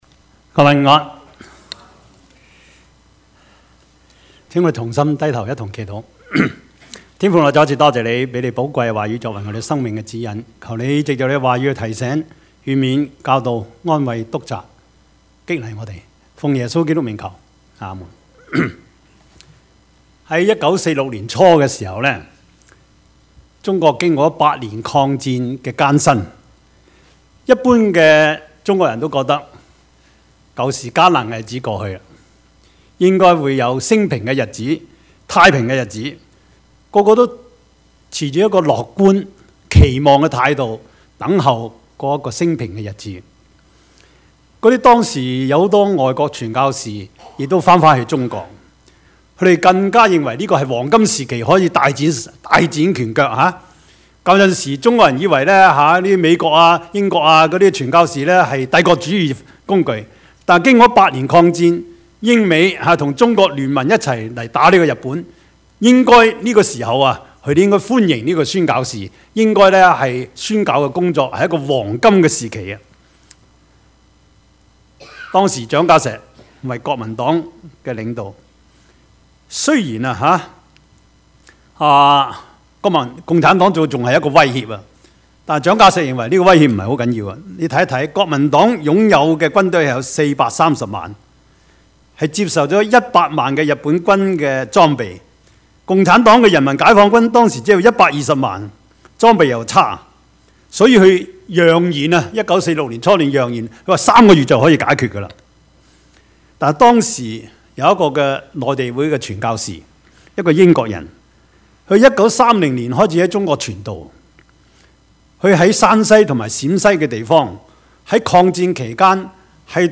Service Type: 主日崇拜
Topics: 主日證道 « 跟從主耶穌 捕風捉影的人生 »